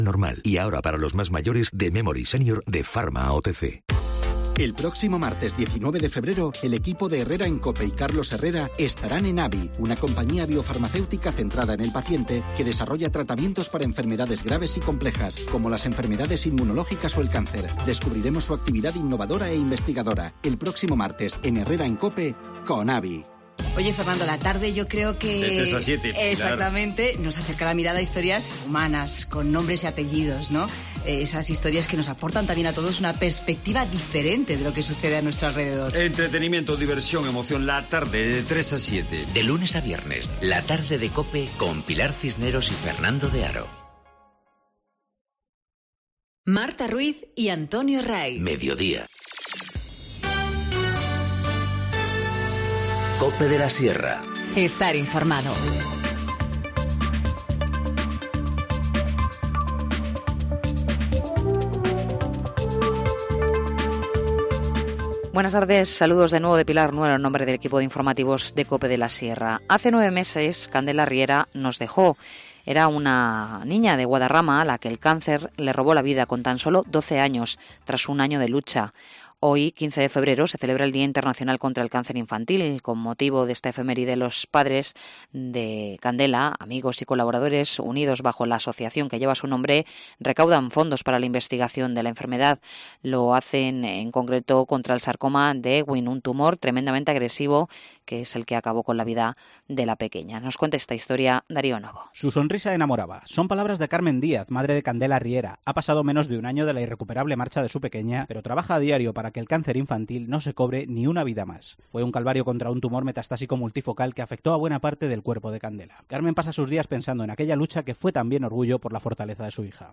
Informativo Mediodía 15 febrero- 14:50h